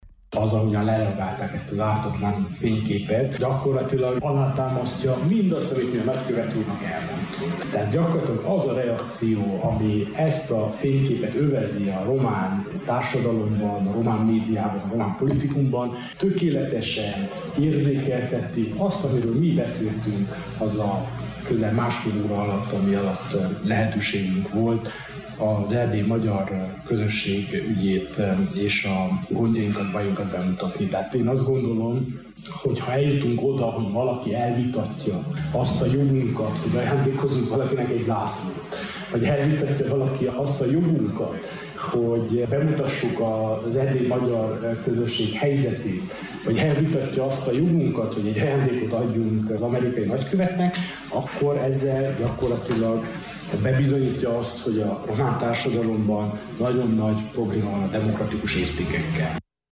Antal Árpád sepsiszentgyörgyi polgármestert hallották.